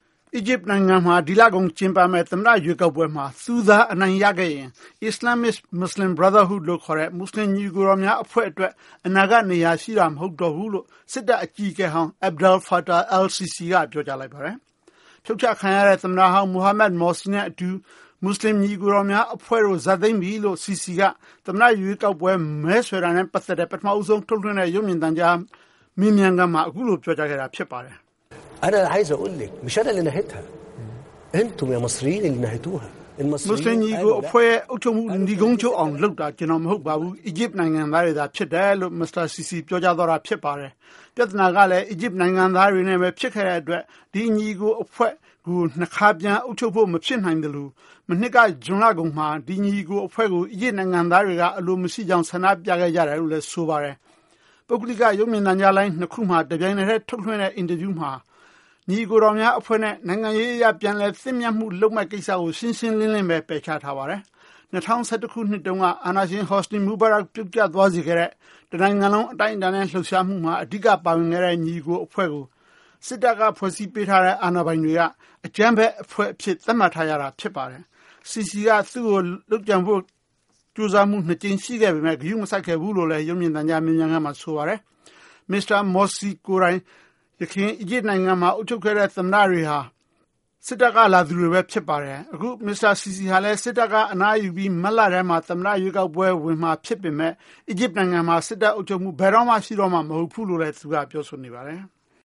ဗိုလ်ချုပ်ကြီးဟောင်း Sissi ရွေးကောက်ပွဲ မဲဆွယ်မိန့်ခွန်း